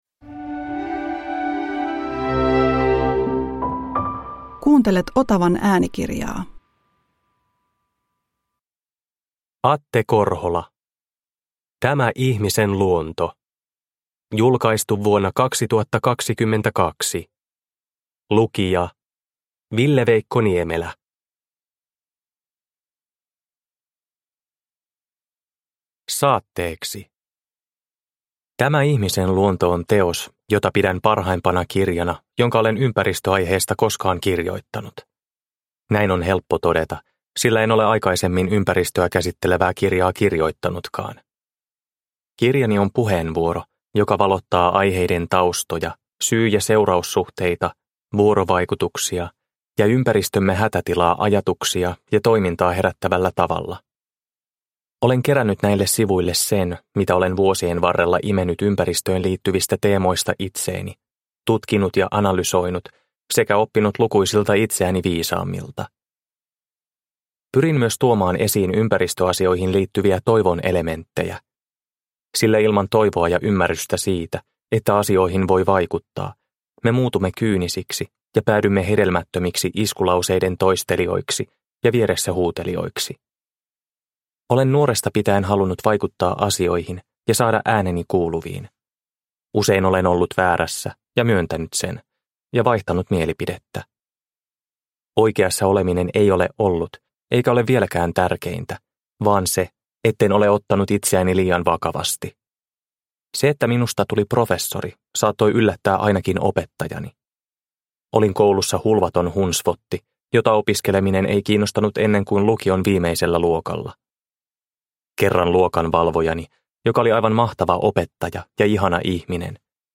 Tämä ihmisen luonto – Ljudbok – Laddas ner